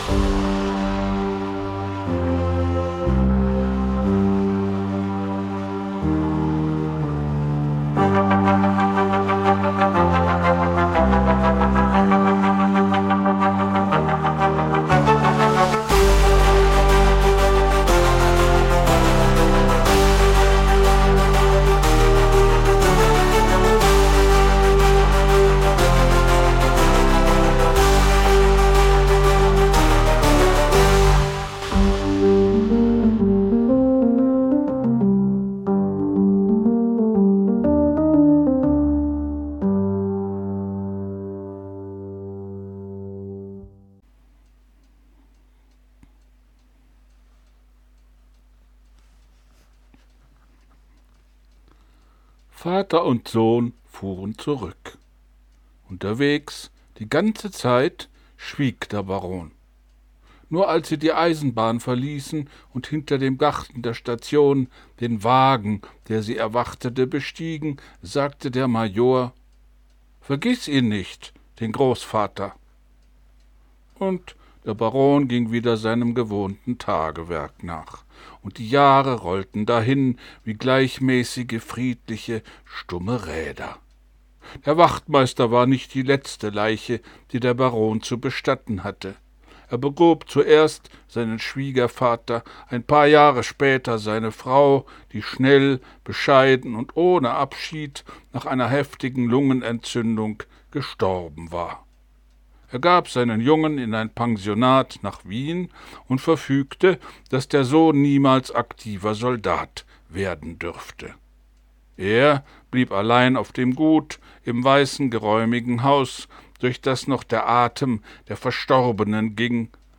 ich lese vor roth radetskymarsch 5